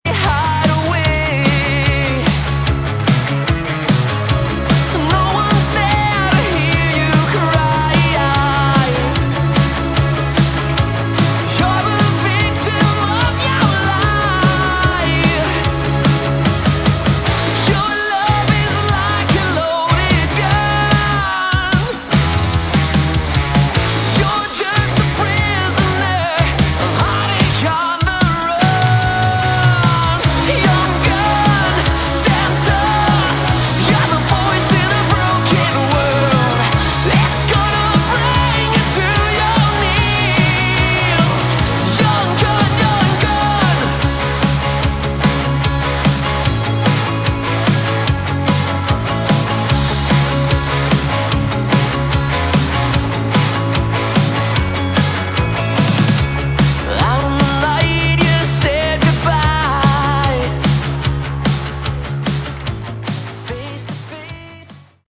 Category: Melodic Rock / AOR
guitar, vocals
keyboards, vocals
bass